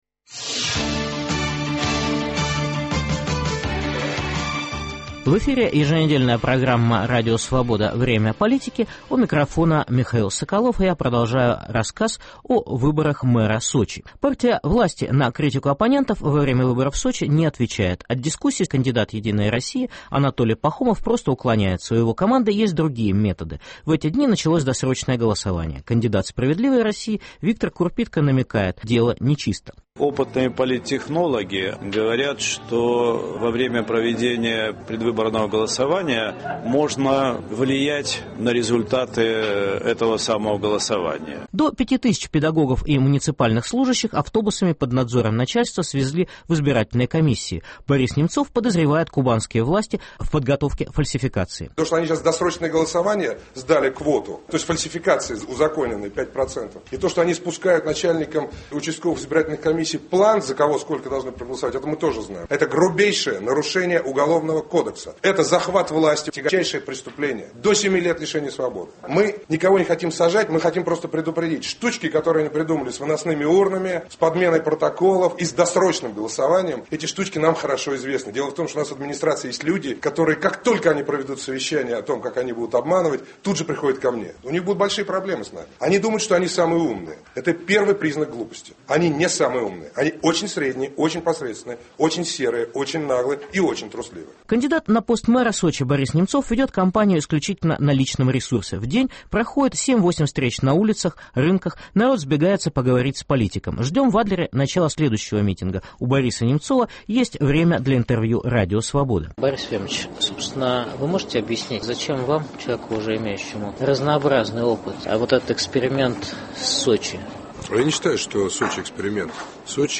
Репортаж о ходе кампании по выборам мэра города Сочи, столицы Олимпийских игр 2014 года